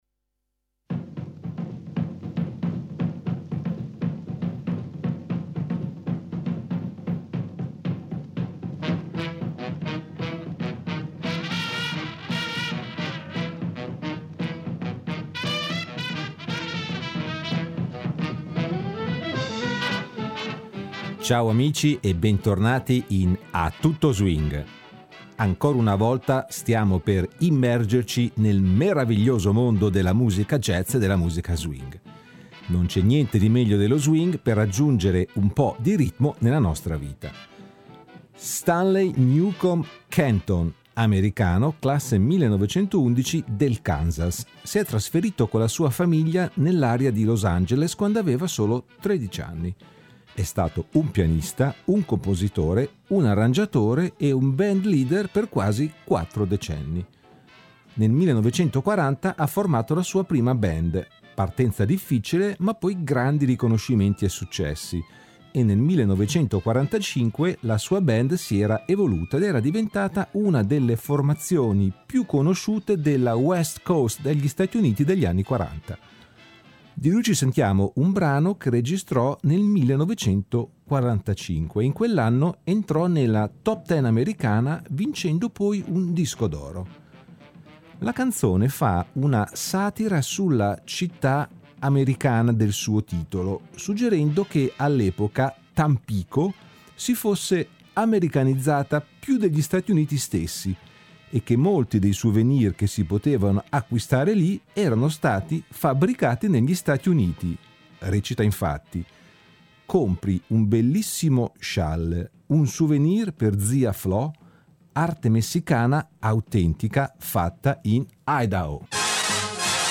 musica swing